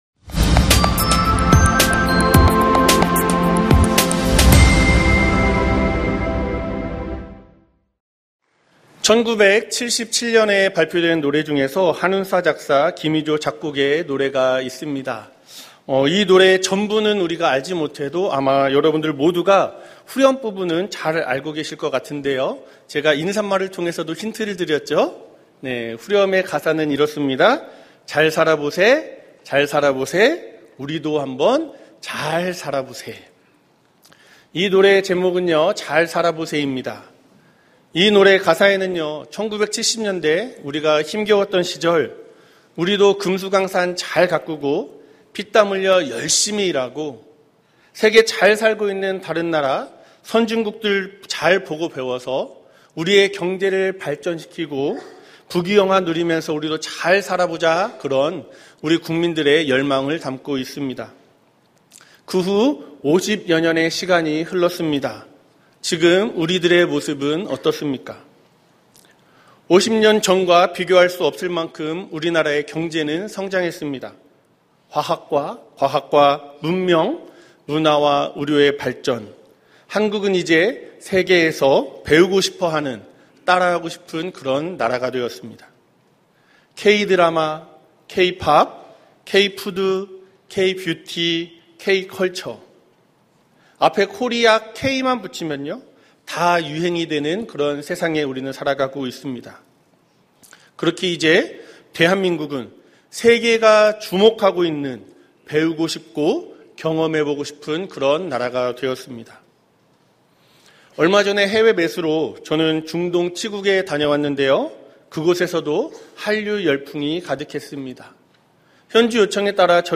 설교 : 주일예배